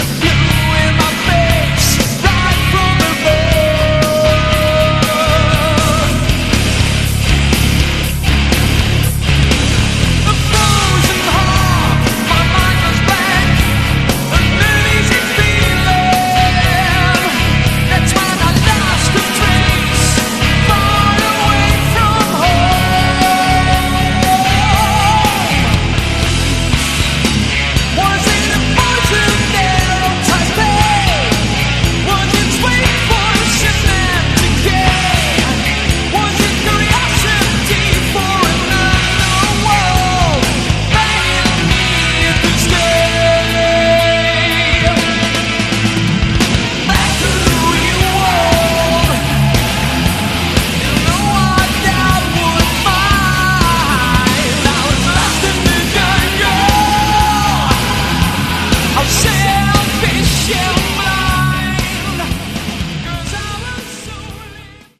Category: Melodic Metal
vocals
guitars
bass
drums